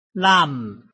臺灣客語拼音學習網-客語聽讀拼-海陸腔-鼻尾韻
拼音查詢：【海陸腔】lam ~請點選不同聲調拼音聽聽看!(例字漢字部分屬參考性質)